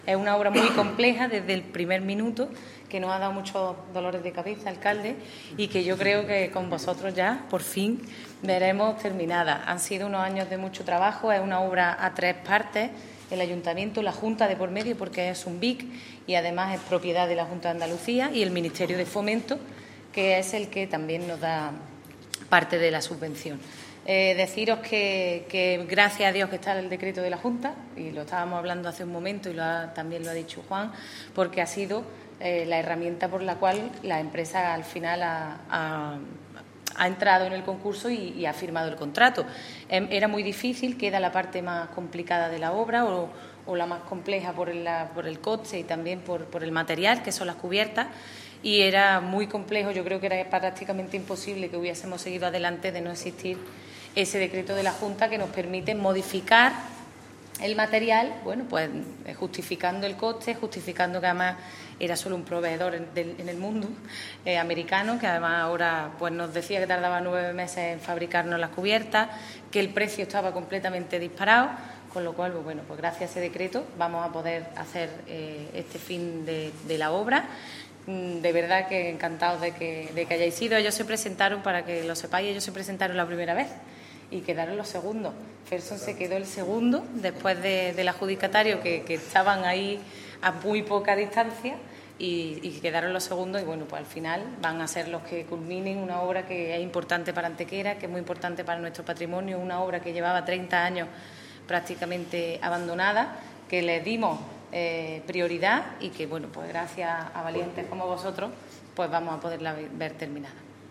El alcalde de Antequera, Manolo Barón, el teniente de alcalde delegado de Contratación, Juan Rosas, y la teniente de alcalde de Patrimonio Histórico, Ana Cebrián, han comparecido hoy en rueda de prensa para anunciar la inminente continuación de las obras de recuperación y rehabilitación de la Villa Romana de la Estación, considerada uno de los principales vestigios arqueológicos de época romana en Andalucía.
Cortes de voz